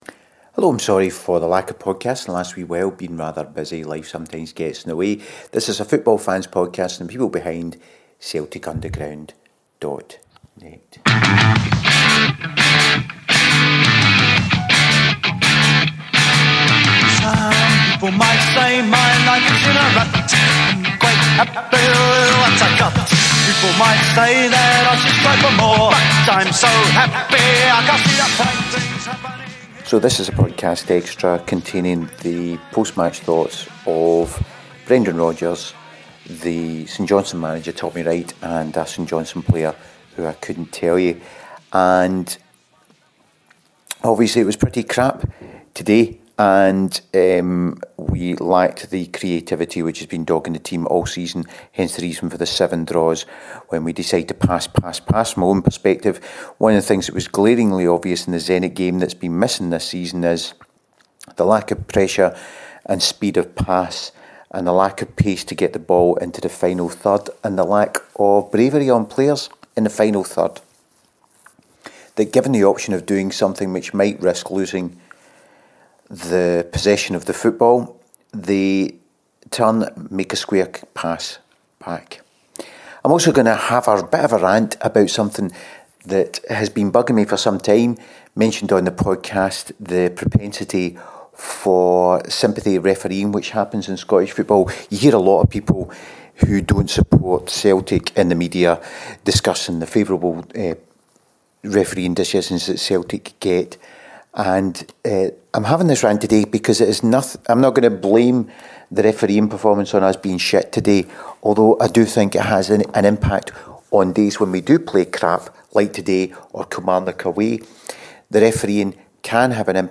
We have the Brendan post match thoughts along with the comments of the St Johnstone manager and a player who I’ve no Idea who he is.